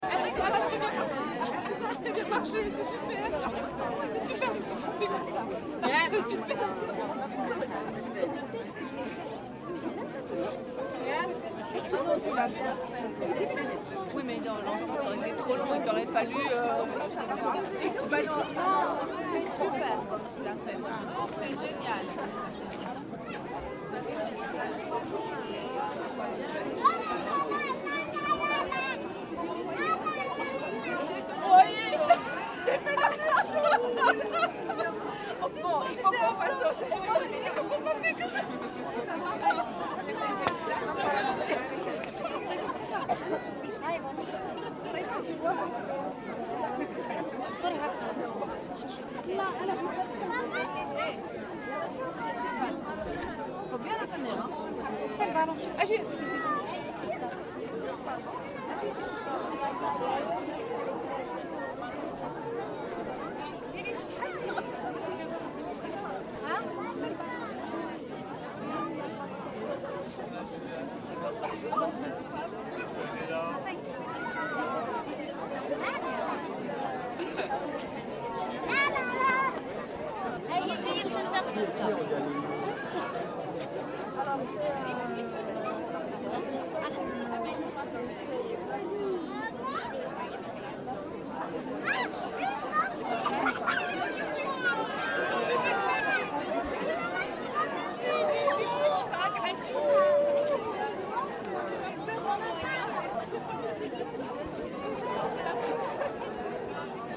Женские голоса на базаре в Марракеше